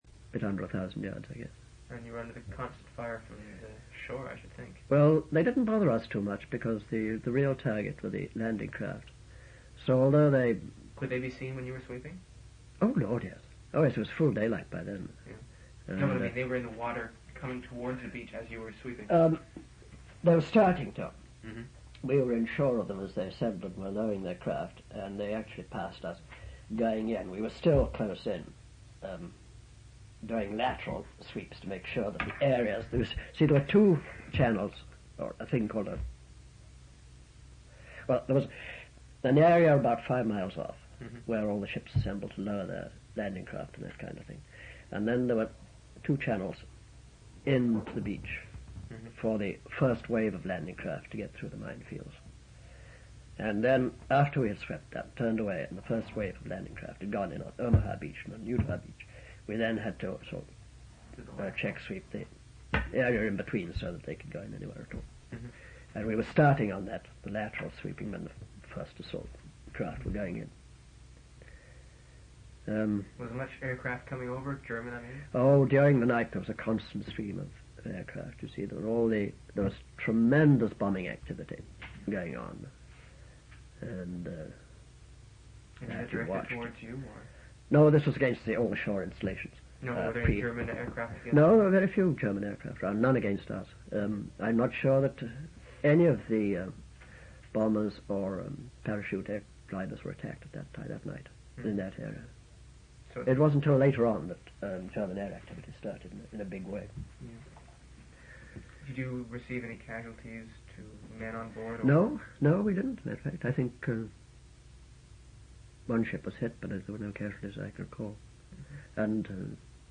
Interview took place on September 9, 16, 1982, June 13, July 8 and August 30, 1983.